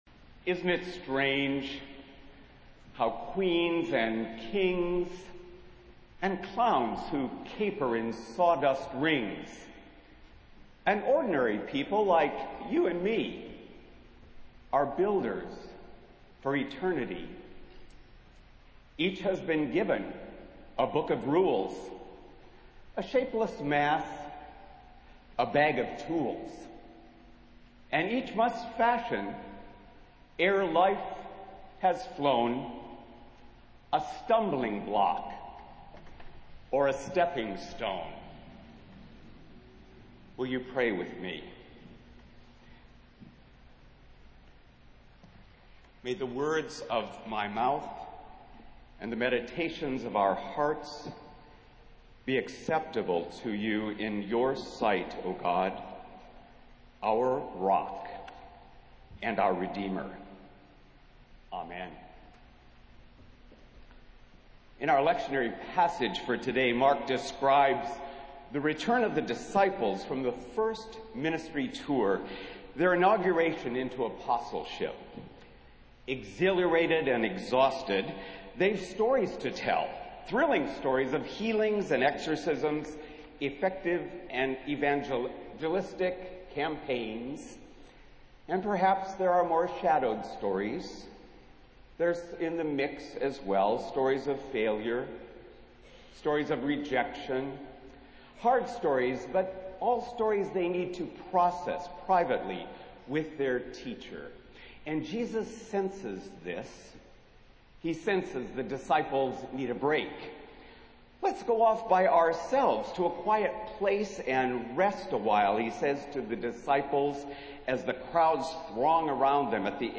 Festival Worship - Eighth Sunday after Pentecost